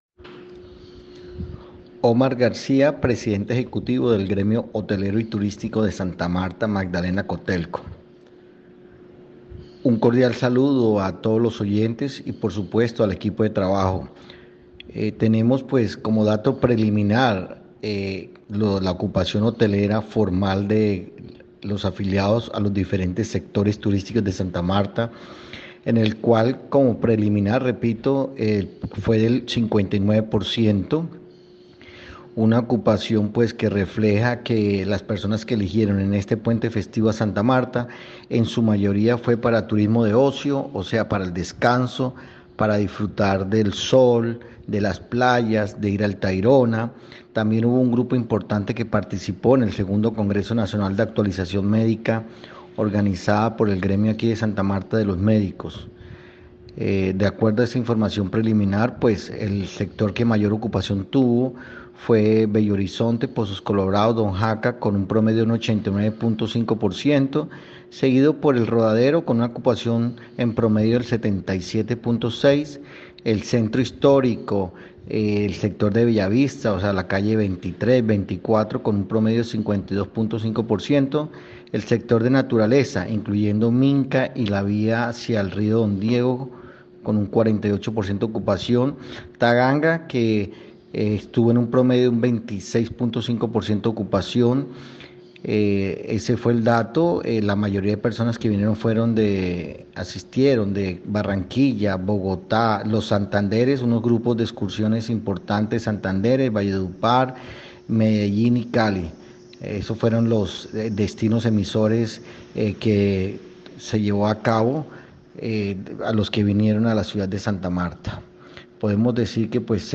AUDIO-COMUNICADO-DE-PRENSA-0505-online-audio-converter.com_.mp3